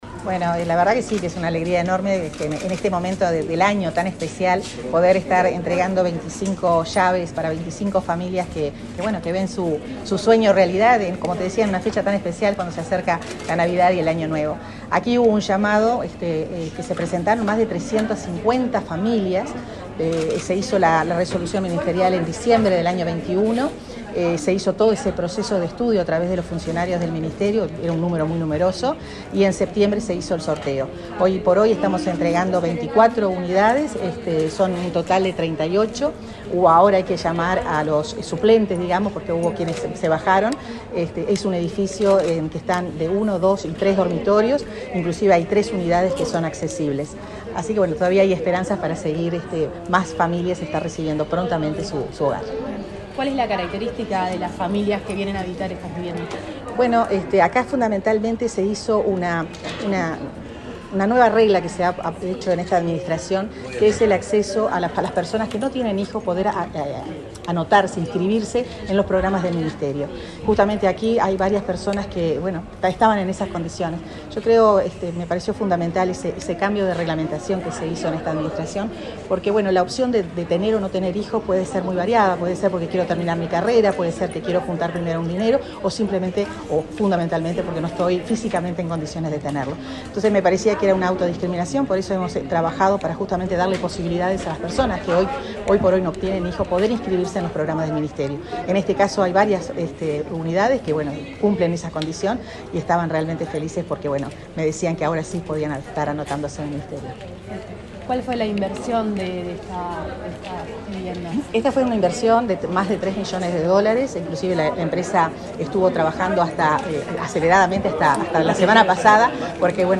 Entrevista a la ministra de Vivienda, Irene Moreira
La ministra Irene Moreira dialogó con Comunicación Presidencial, luego de participar en el acto de entrega de casas a 26 familias en el barrio Unión,